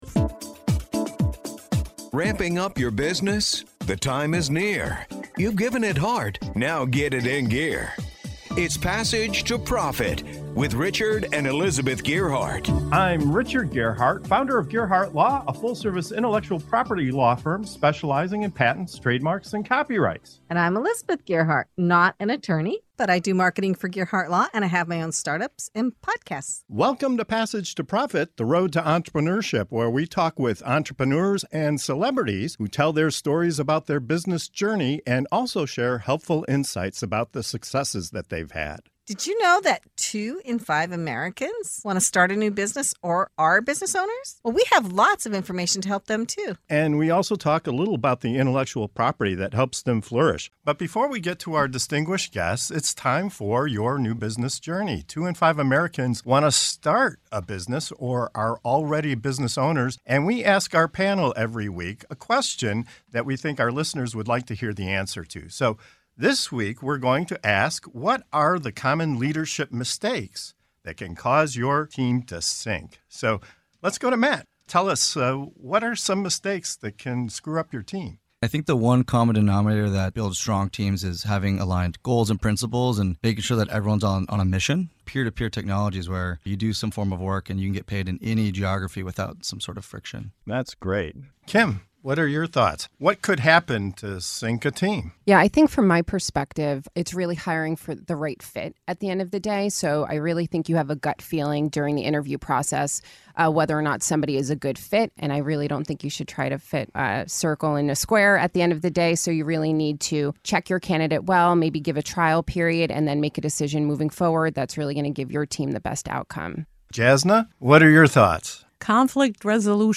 In this segment of Your New Business Journey on Passage to Profit Show, our panel dives into the leadership pitfalls that can sink even the most promising teams.